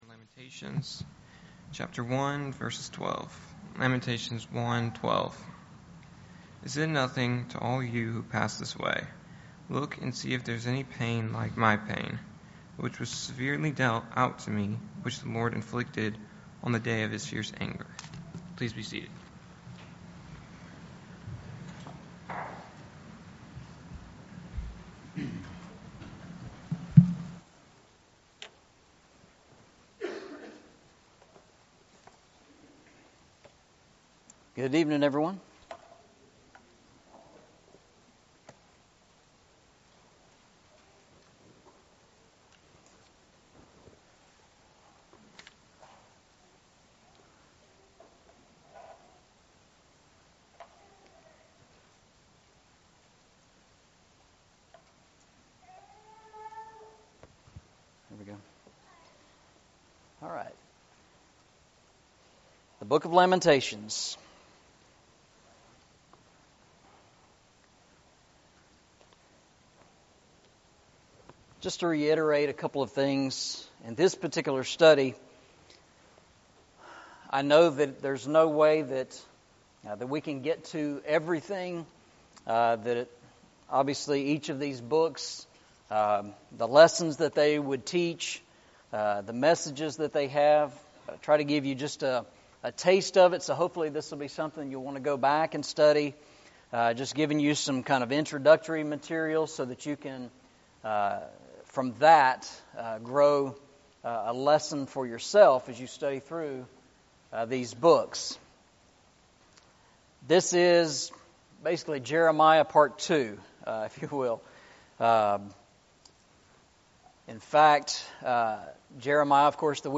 Eastside Sermons
Service Type: Sunday Evening